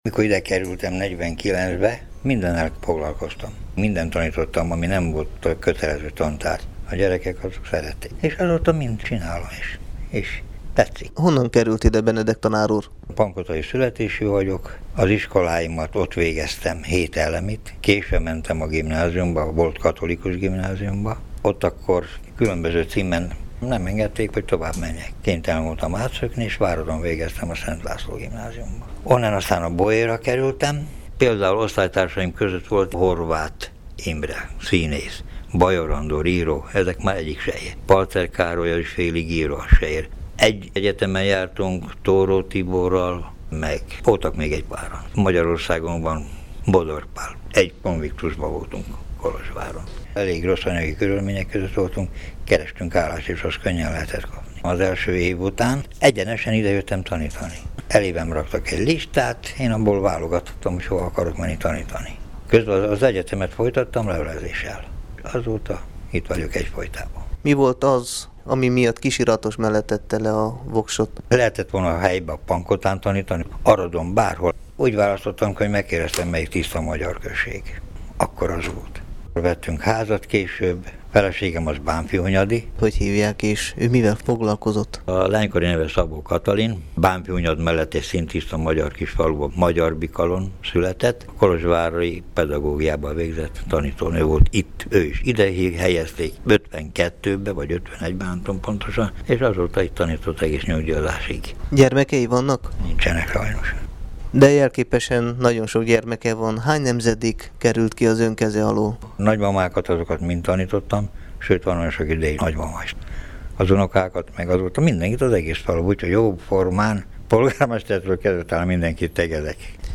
Hallgassa meg az akkor készült interjút!